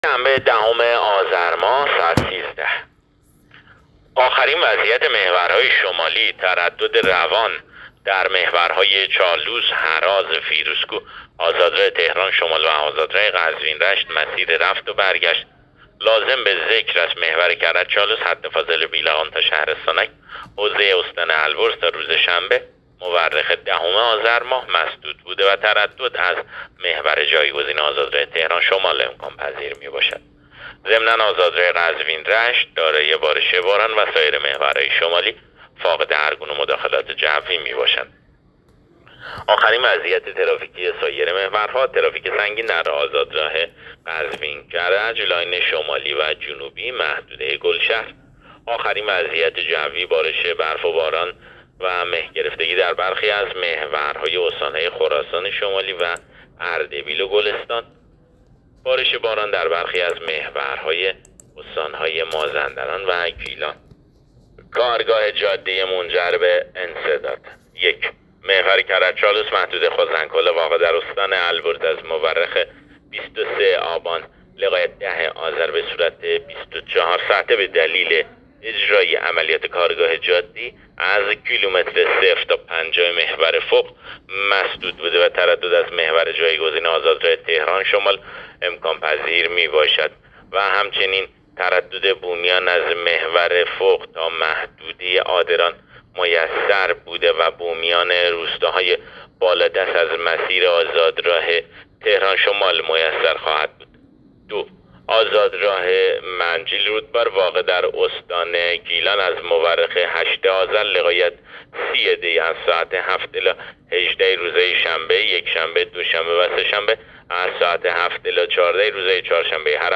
گزارش رادیو اینترنتی از آخرین وضعیت ترافیکی جاده‌ها تا ساعت ۱۳ دهم آذر؛